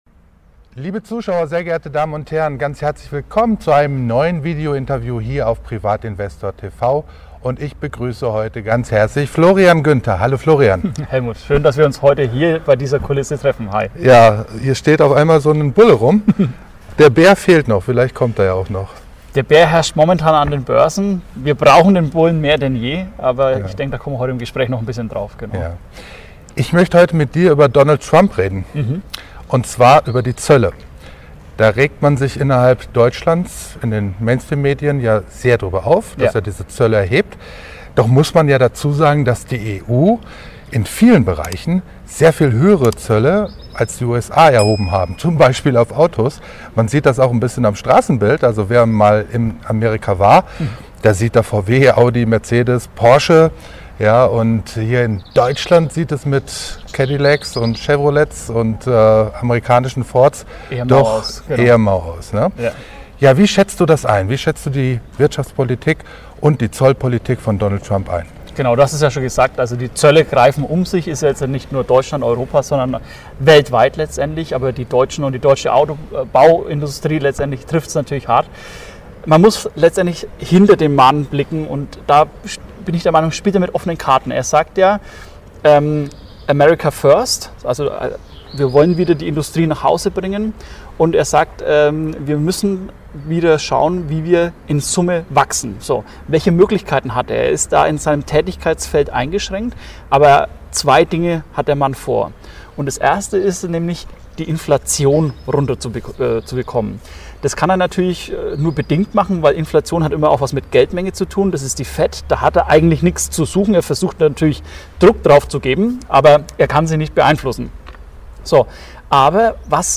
In diesem Interview